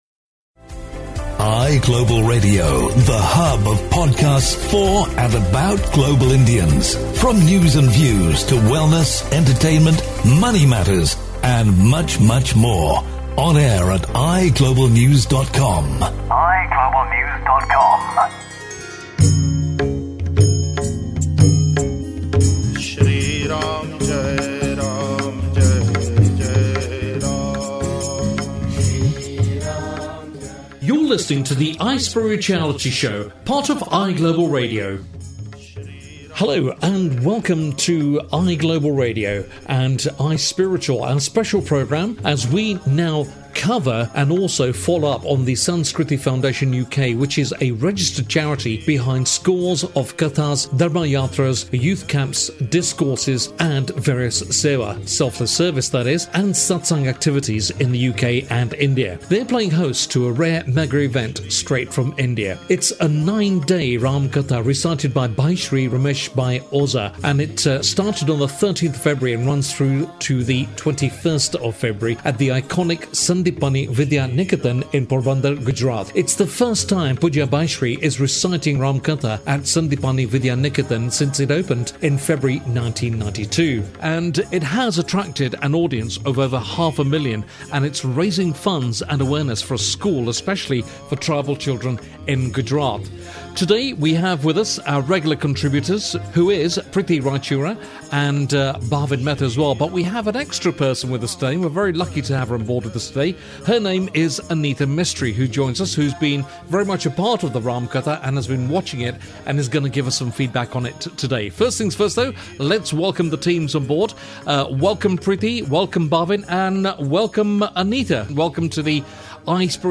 In this episode, ‘Ram Katha’ devotees reflect upon the message that the Tulsidas ‘Ramayana’ has no bad characters, only theatrical roles played out in order to provide valuable lessons.